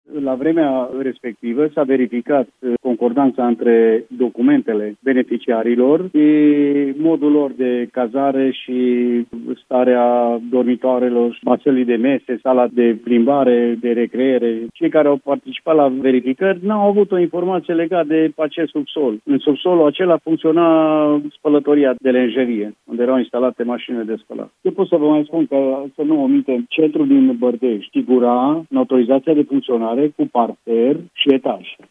Azilul Căsuța lu’ Min din  Bărdești, județul Mureș, a fost controlat pe 14 iulie și amendat, cea mai mare amendă fiind dată de pompieri, însă nimeni nu a verificat subsolul spune, la Europa FM, subprefectul județul Mureș, Nicolae Florin Pălășan: